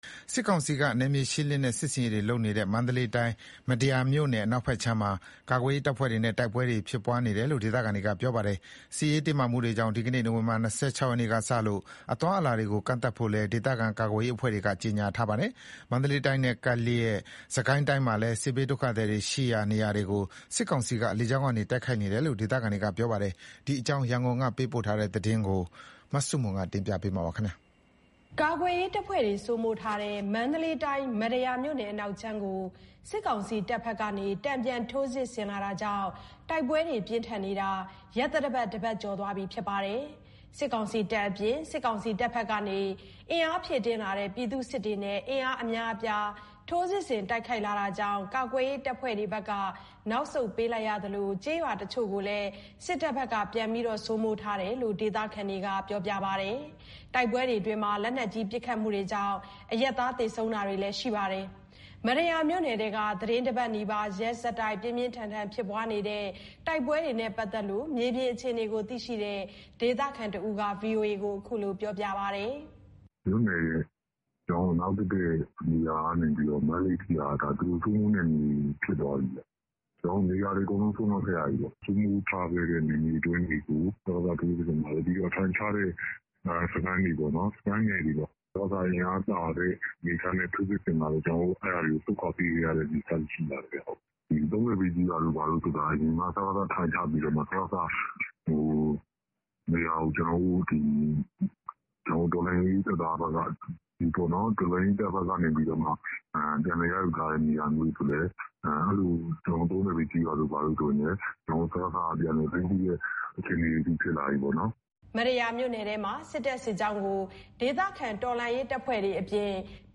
မတ္တရာမြို့နယ်ထဲက သီတင်းတပတ်နီးပါး ရက်ဆက်တိုက် ပြင်းပြင်းထန်ထန် ဖြစ်ပွားနေတဲ့ တိုက်ပွဲတွေနဲ့ပတ်သက်ပြီးမြေပြင်အခြေအနေကိုသိရှိတဲ့ ဒေသခံတဦးက အခုလို ပြောပါတယ်။
စစ်ကိုင်းမြို့နယ်ထဲက တလိုင်းကျေးရွာမှာ မတ္တရာမြို့နယ်ထဲက တိုက်ပွဲတွေကြောင့် စစ်ကိုင်းဘက်ကို တိမ်းရှောင်လာတဲ့စစ်ဘေးရှောင်တွေရှိပြီး လေကြောင်းပစ်ခတ်တာကြောင့် ဘေးလွတ်ရာ နေရာတွေဆီကို ထပ်မံတိမ်းရှောင်နေရတယ်လို့ဒေသခံတဦးက အခုလို ပြောပါတယ်။